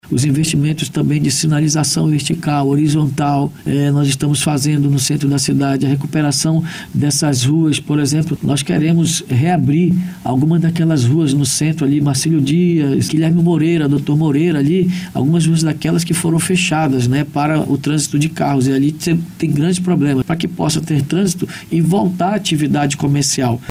A afirmação ocorreu durante entrevista na BandNews Difusora nessa quarta-feira, 03.